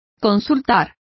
Complete with pronunciation of the translation of confer.